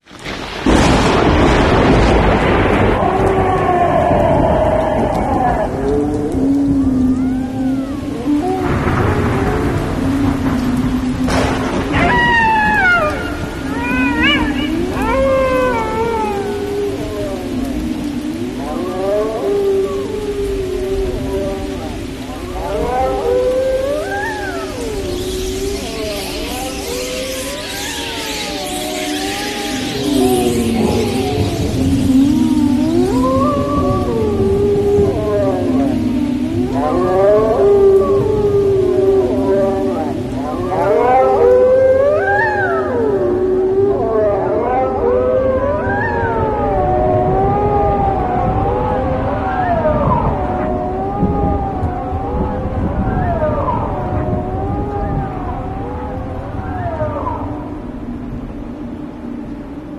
ভূতের এই সাউন্ড টা কিন্তু sound effects free download
ভূতের এই সাউন্ড টা কিন্তু ভয় পাওয়ার মত